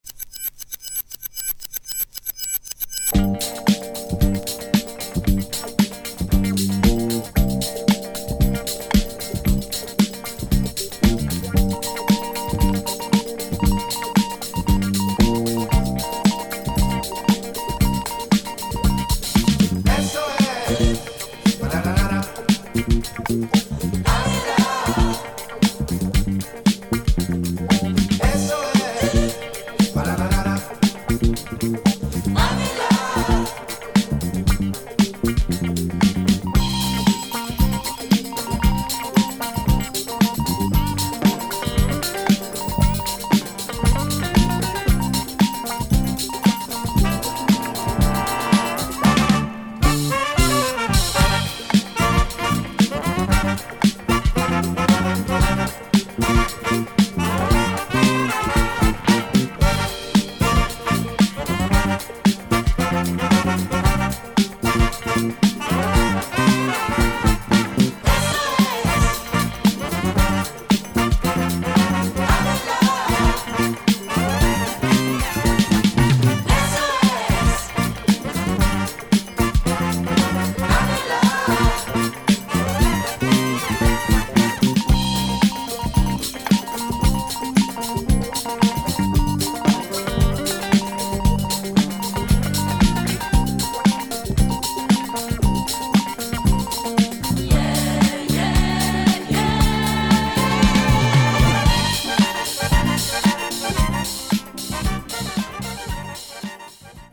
funky clubby